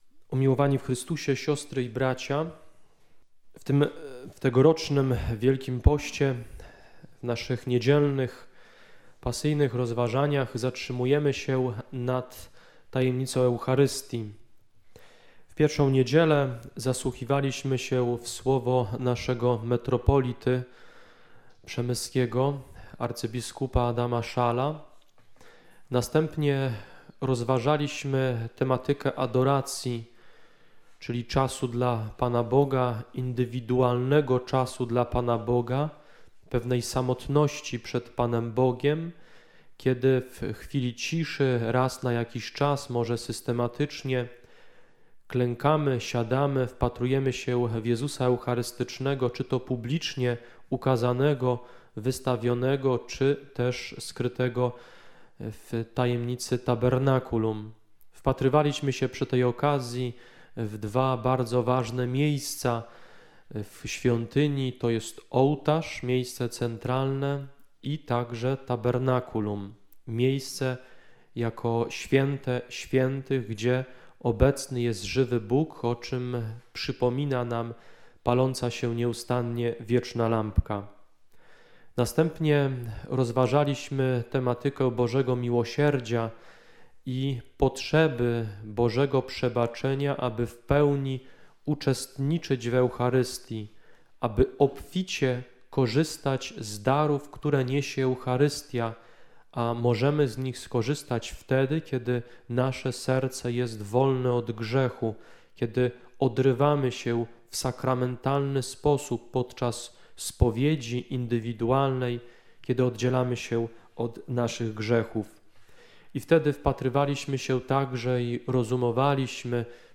IV Niedziela WP – homilia: Liturgia Słowa - Rektorat św. Maksymiliana w Sanoku
IV Niedziela Wielkiego Postu – homilia: